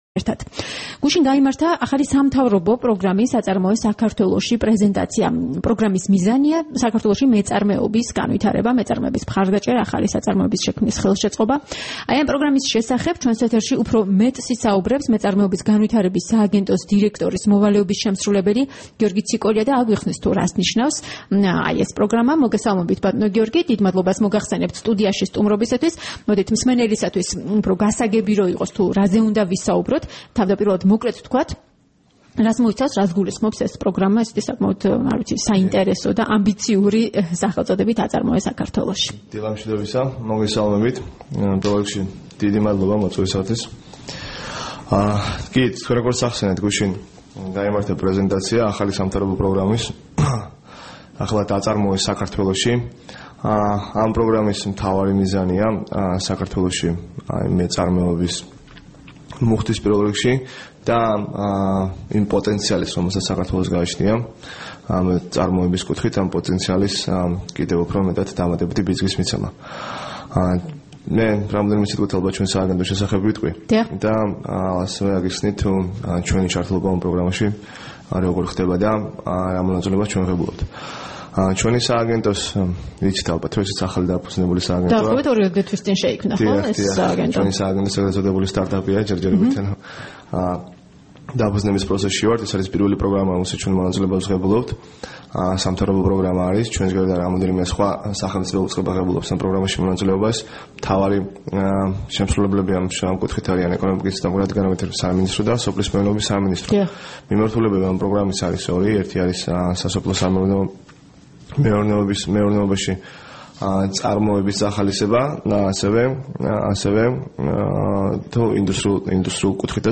20 მაისს რადიო თავისუფლების დილის გადაცემის სტუმარი იყო გიორგი ციკოლია მეწარმეობის განვითარების სააგენტოს დირექტორის მოვალეობის შემსრულებელი.